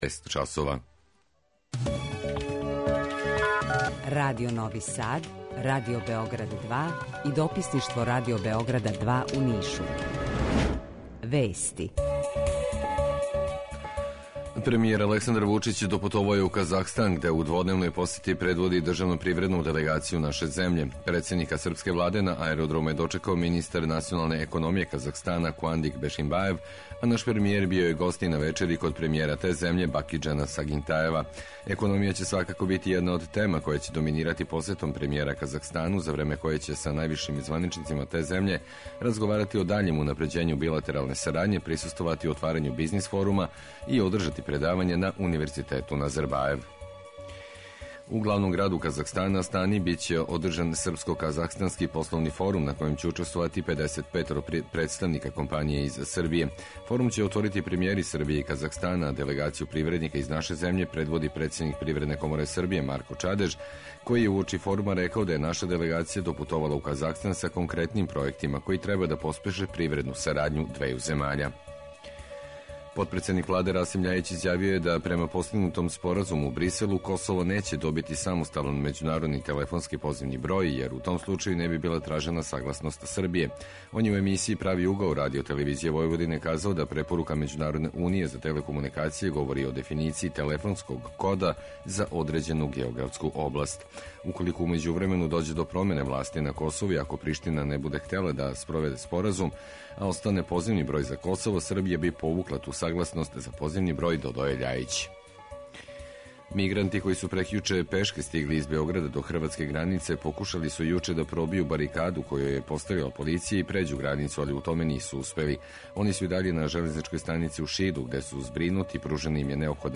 У два сата ту је и добра музика, другачија у односу на остале радио-станице.